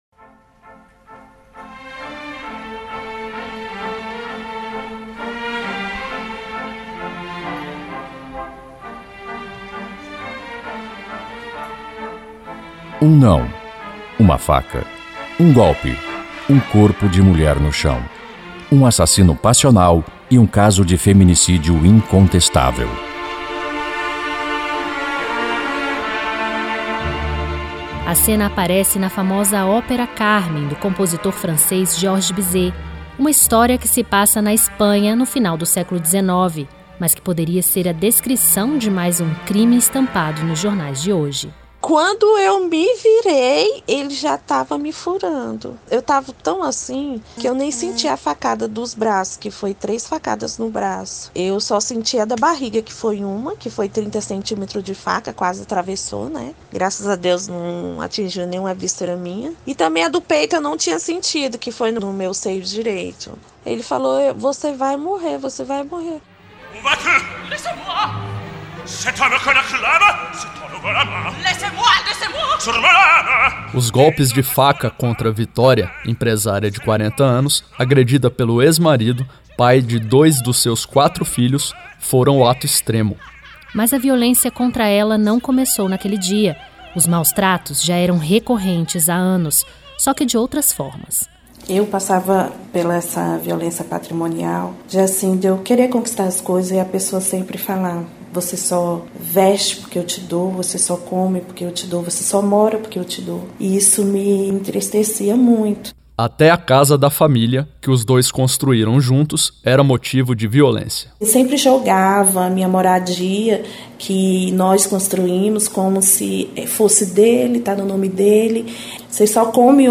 E é sobre este tema que a Rádio Senado apresenta uma série especial com cinco reportagens nas quais as mulheres relatam o que elas e os filhos vivem cotidianamente.